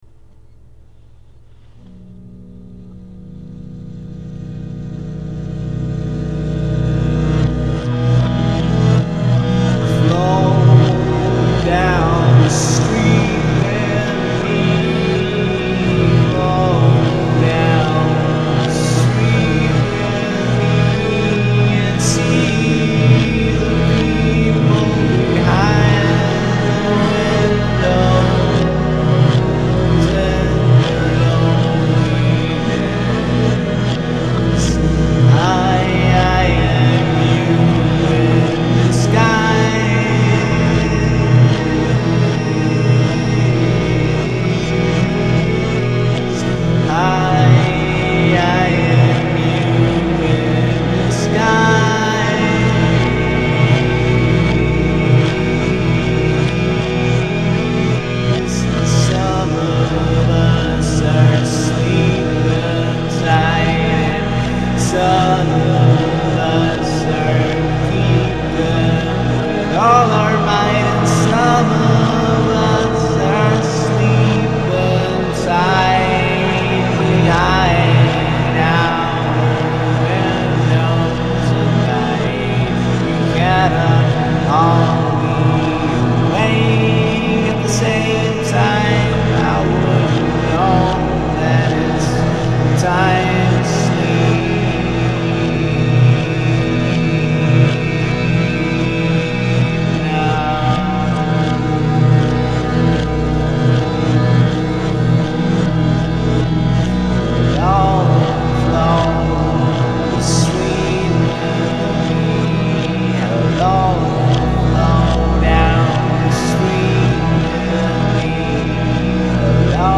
Põhirõhk on voolaval meeleolul. Kui tausta valmis sain, improviseerisin lihtsalt sõnad peale.
Kusjuures helid on küll töödeldud, aga midagi põhimõtteliselt "elektroonilist" siin pole. Pearütmi tegin näiteks akustilise kitarriga, mille alumise keele küljes kõlkuv kirjaklamber tekitab eksootilise kõrina. Siis käänasin selle heli tagurpidi kulgema ja venitasin aegluupi. Siis hakkasin tekkinud meloodiat jällegi akordioniga "toestama".